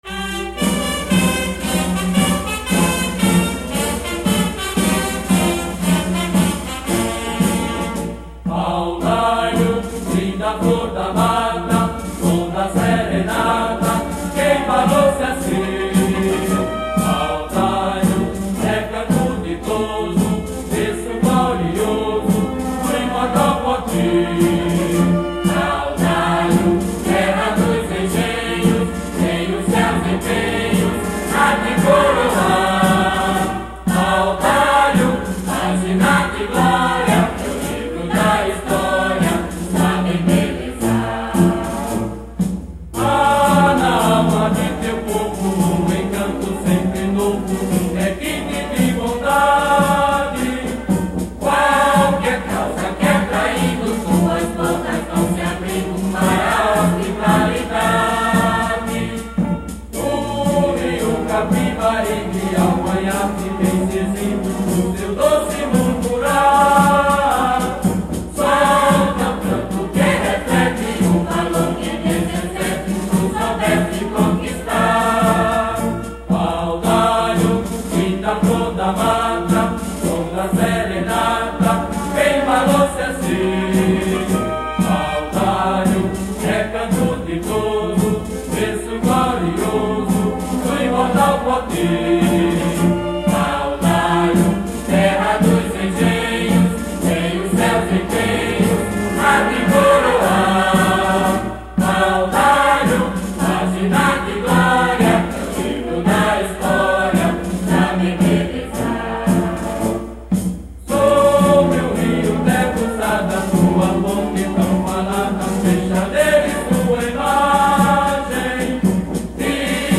Hino
Hino-oficial-de-Paudalho.mp3